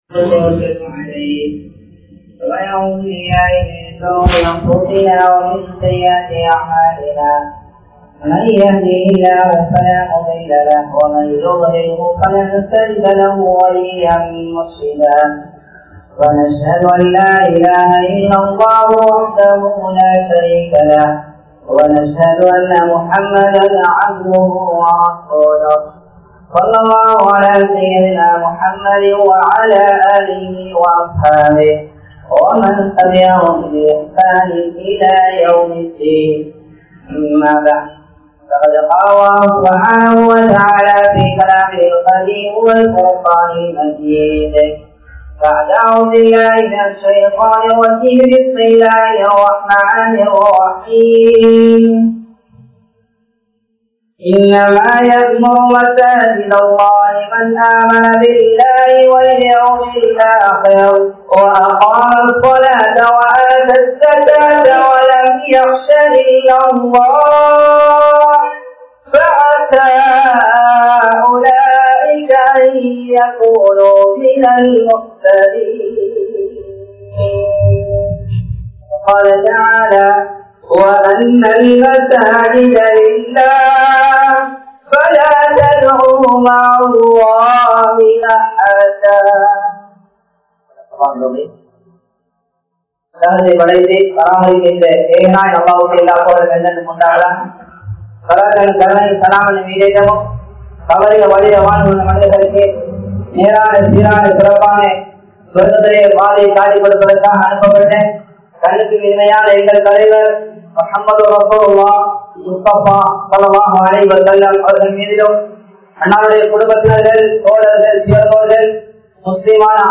Masjidhin Niruvaaha Saifaikku Yaar Thahuthi? (மஸ்ஜிதின் நிருவாக சபைக்கு யார் தகுதி?) | Audio Bayans | All Ceylon Muslim Youth Community | Addalaichenai
Colombo 14, Layards Broadway, Jamiul Falah Jumua Masjidh